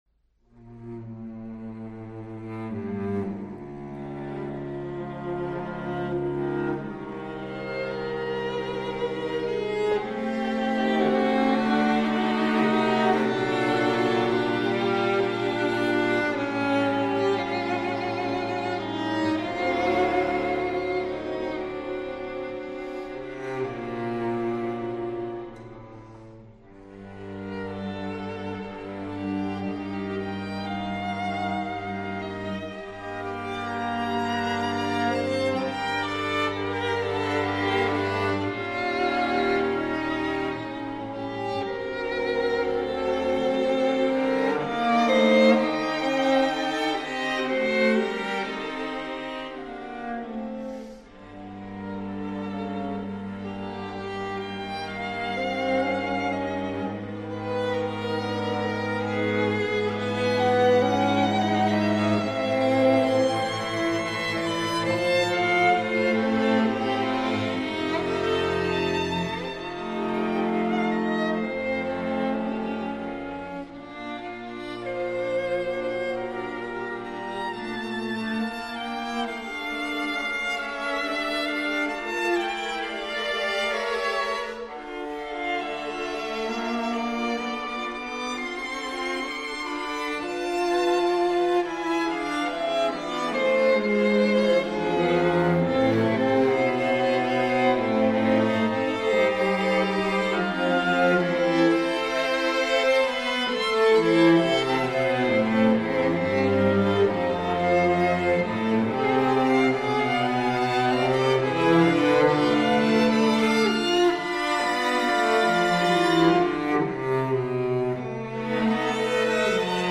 Soundbite 3rd Movt